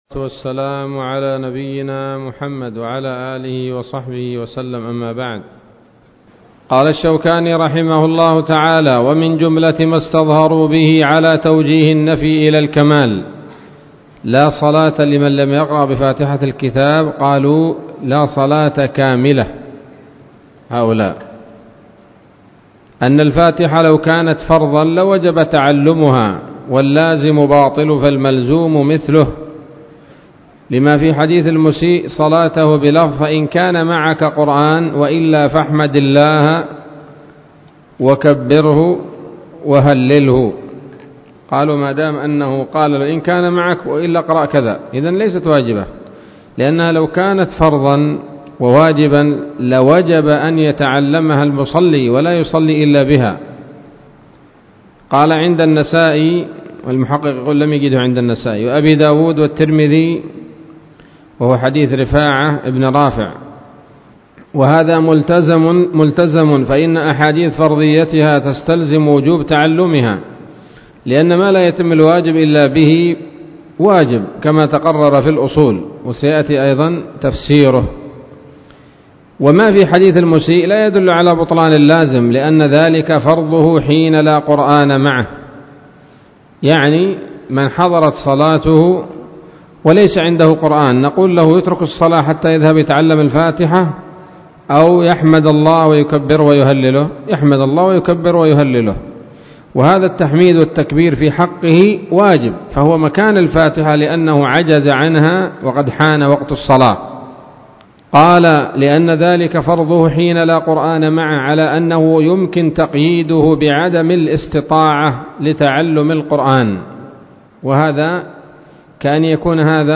الدرس التاسع والعشرون من أبواب صفة الصلاة من نيل الأوطار